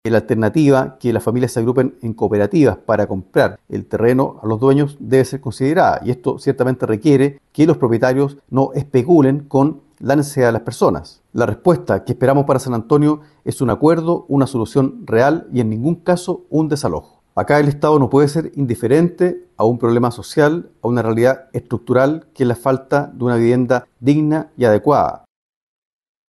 Por su parte, el diputado por la Región de Valparaíso y miembro de la comisión de vivienda de la Cámara de Diputadas y Diputados, Luis Cuello, afirmó que se debe evitar el desalojo e interpeló directamente a los propietarios del terreno, señalando que estos no deben especular con la necesidad de las personas.
cuna-desalojo-san-antonio-luis-cuello.mp3